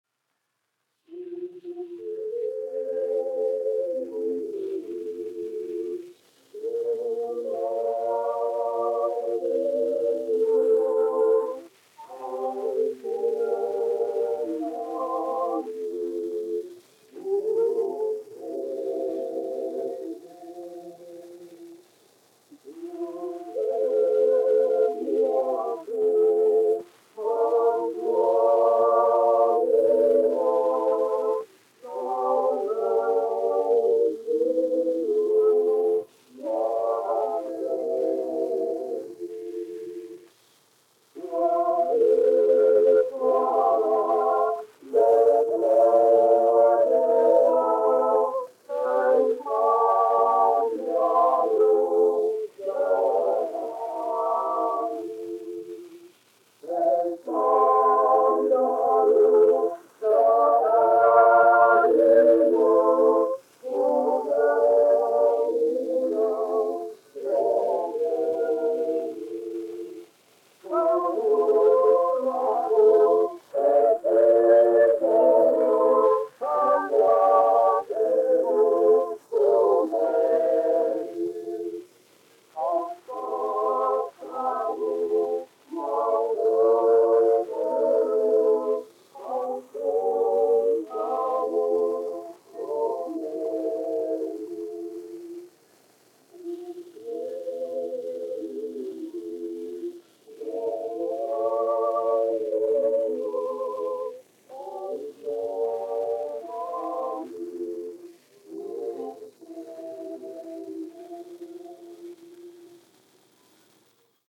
Andrejs Jurjāns, 1856-1922, aranžētājs
1 skaņuplate : analogs, 78 apgriezieni min., mono ; 18 cm
Kori (jauktie)
Latviešu tautasdziesmas